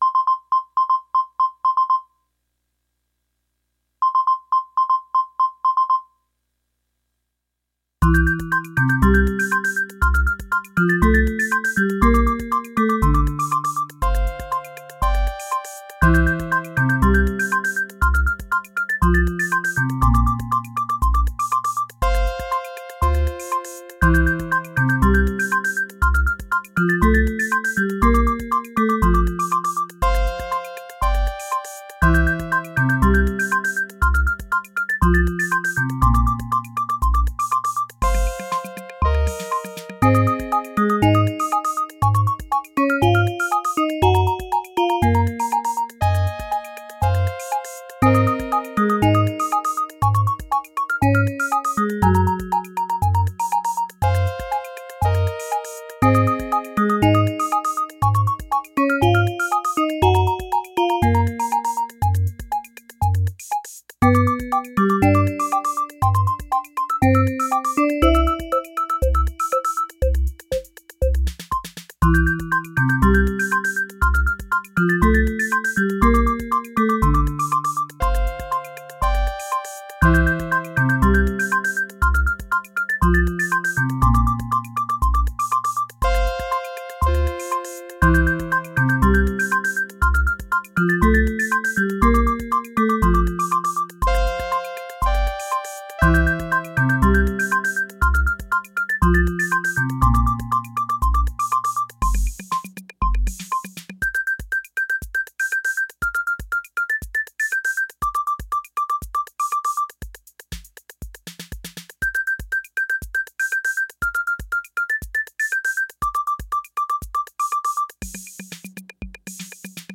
and (louder bass)
• Synthstrom Deluge: Drum sequence using 808 presets
The song is in mono.
And of course the drum sequence is cheesy.